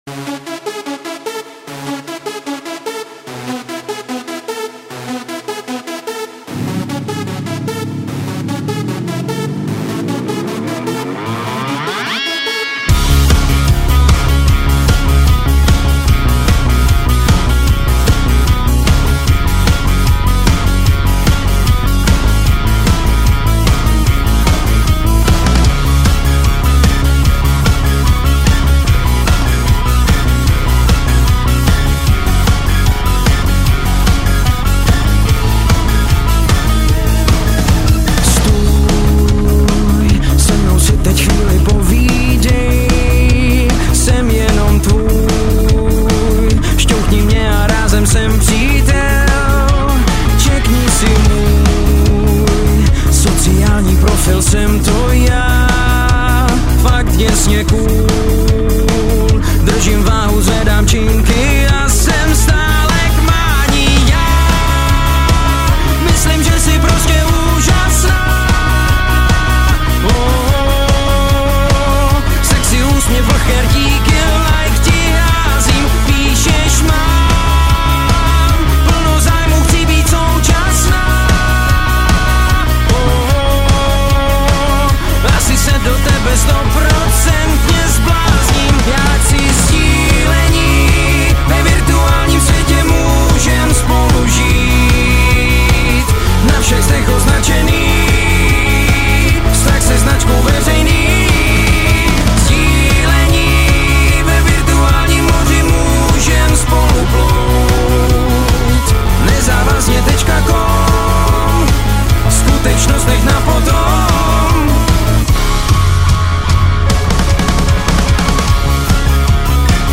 Žánr: Rock
Rocková muzika s prvky popu.